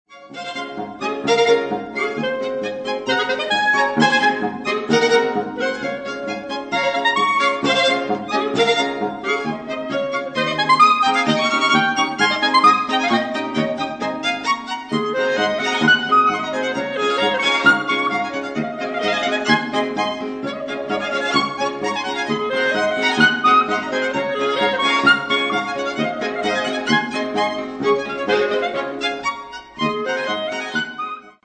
* Quartett mit G-Klarinette
Steinerner Saal, Musikverein Wien
Polka schnell * 2'25